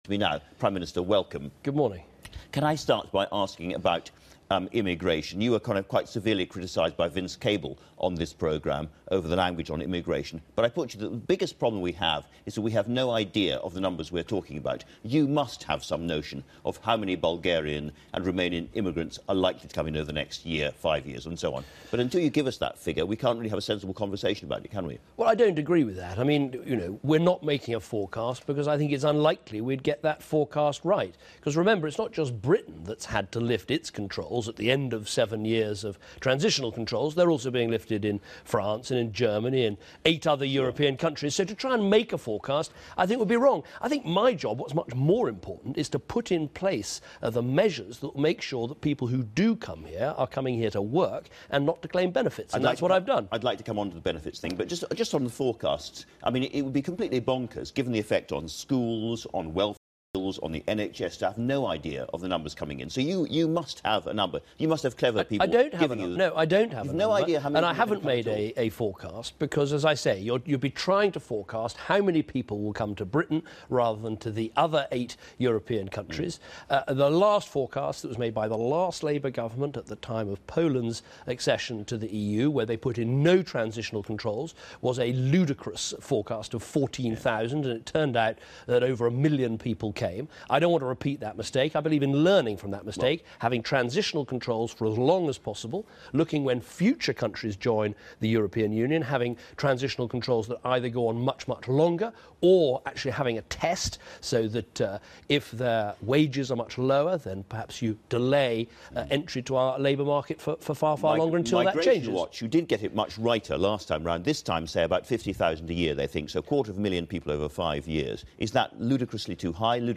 The Prime Minister repeatedly dodged questions about pensioner benefits in his interview on BBC One.